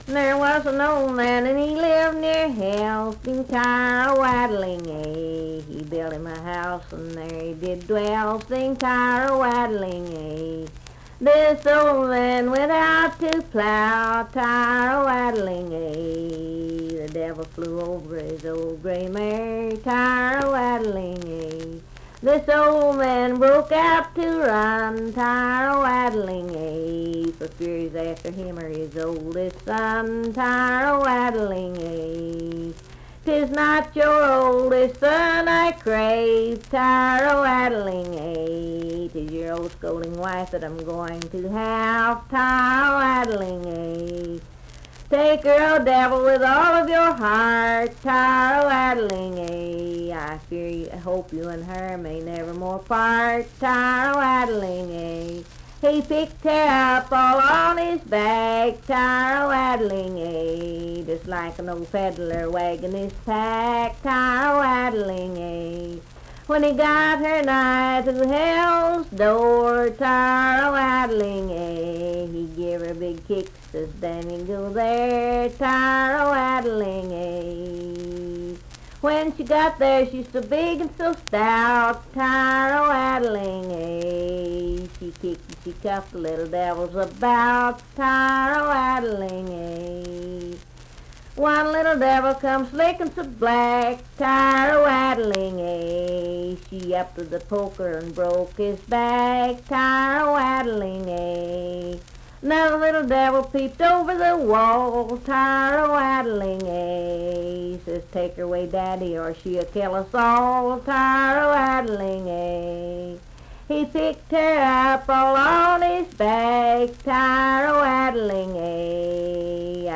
Unaccompanied vocal music
Verse-refrain 12(4w/R).
Voice (sung)